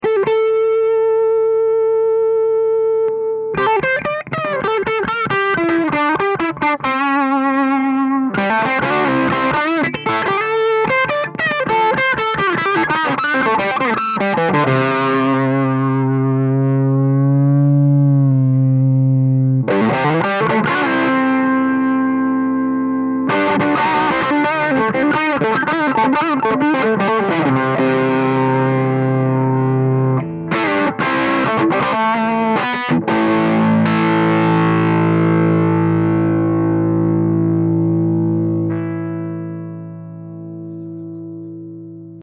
- Copie du son du canal "Blue" du Bogner XTC.